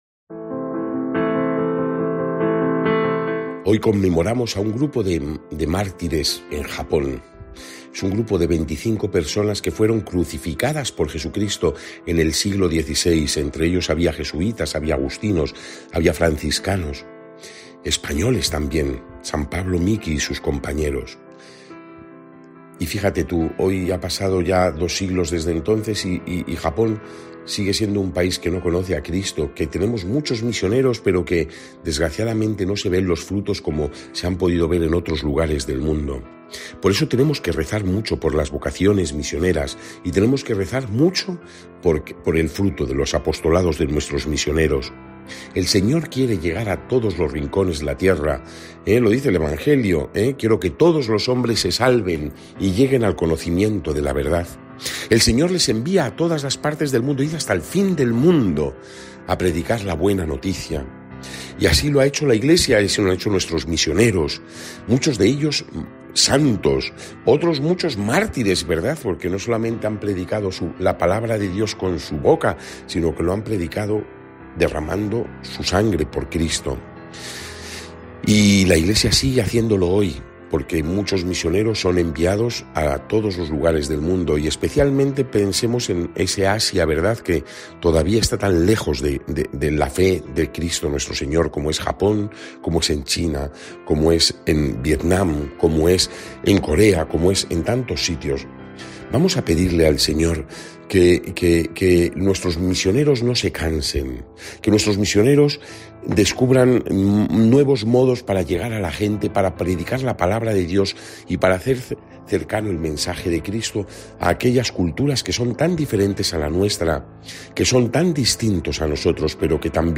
Lectura del santo evangelio según san Marcos 7, 1-13